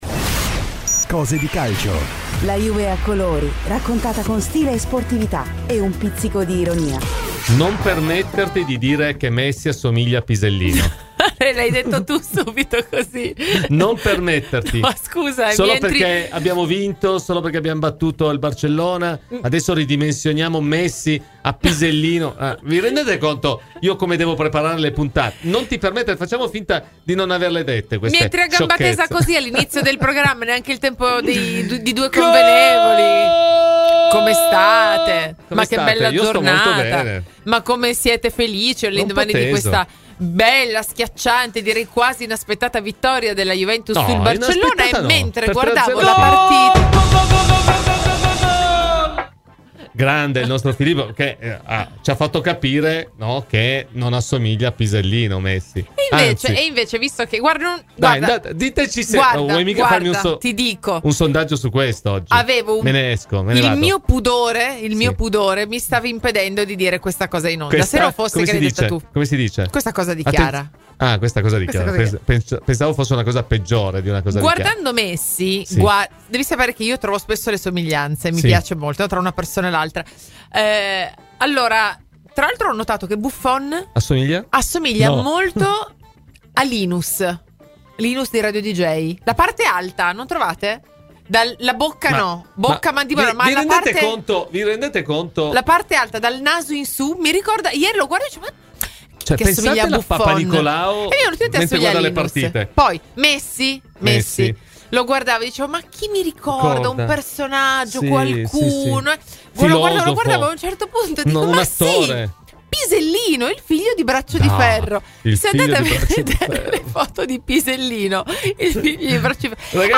Clicca sul podcast in calce per l’intervista completa e la trasmissione integrale.